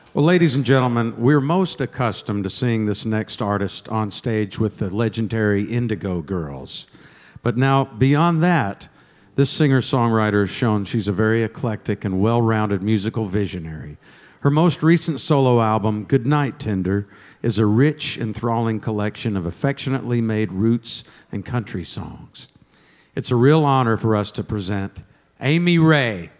(recorded from webcast)
01. introduction (0:26)